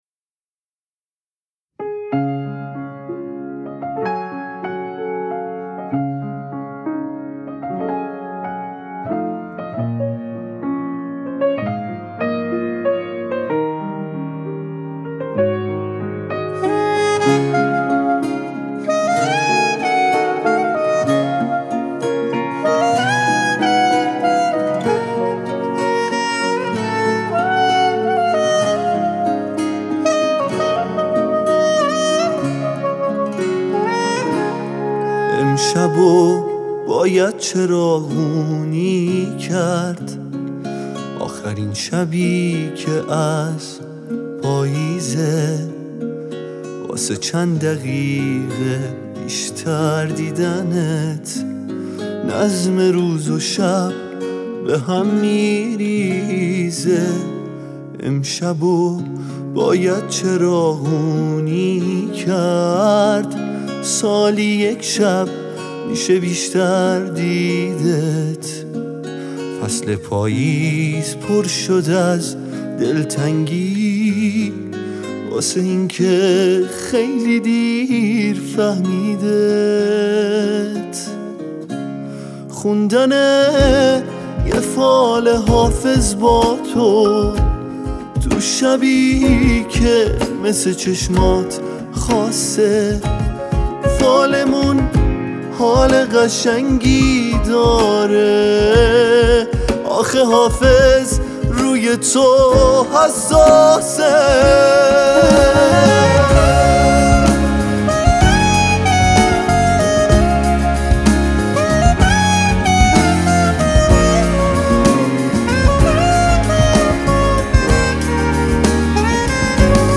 آهنگ هاي شاد